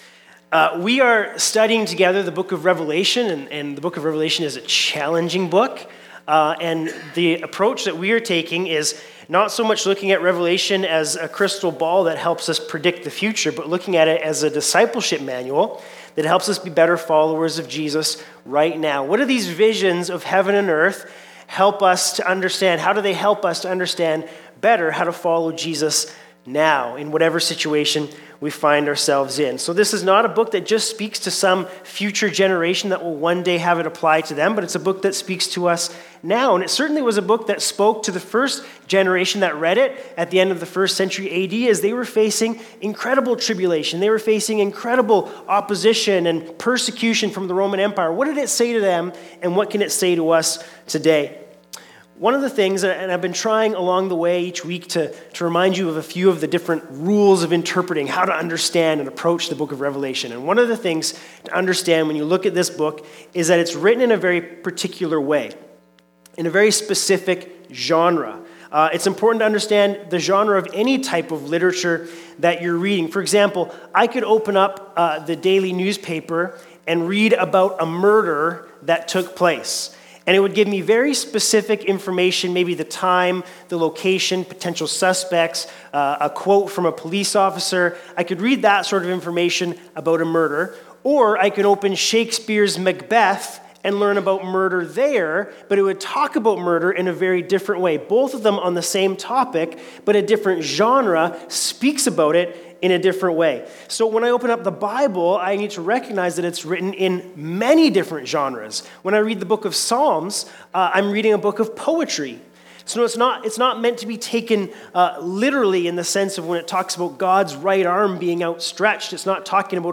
Sermons | Bethel Church Penticton